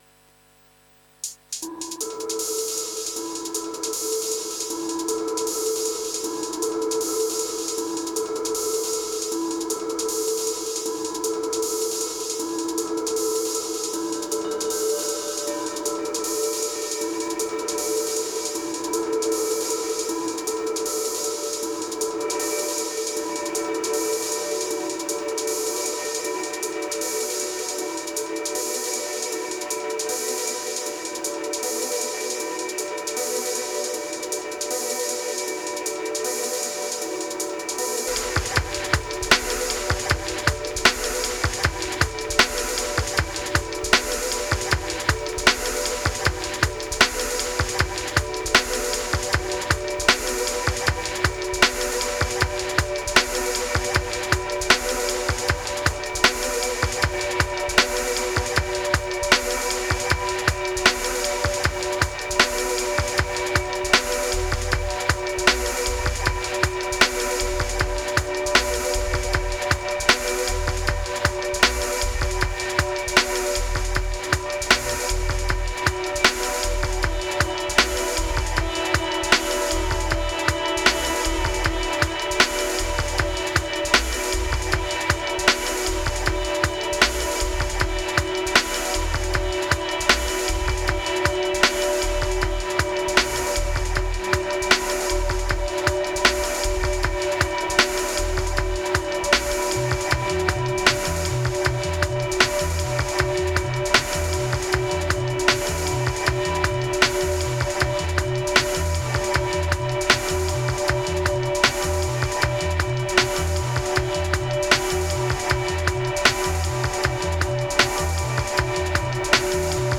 Dub Ambient Scholar Mpc Ethic Attic Mesmerized Shimer Glow